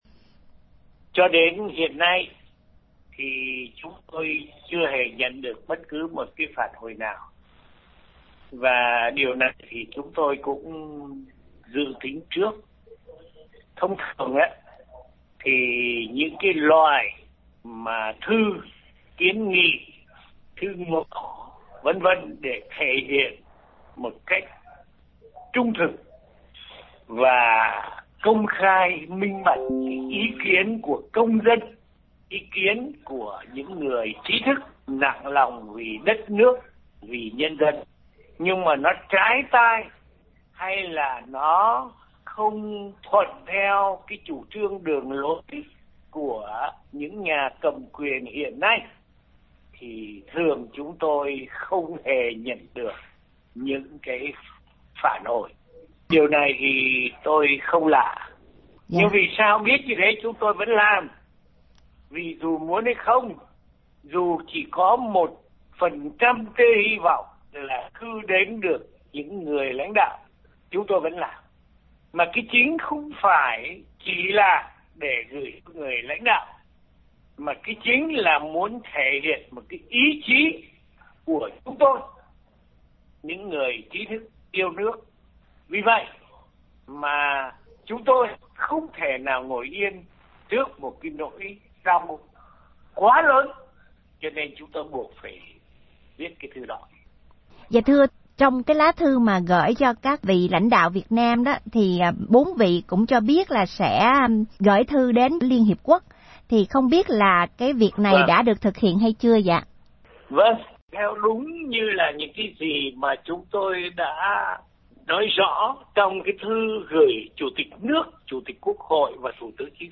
vào tối ngày 4/2 dành cho RFA một cuộc phỏng vắn xoay quanh thông tin vừa nêu.